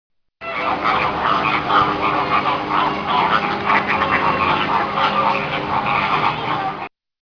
Flamingos doing their thing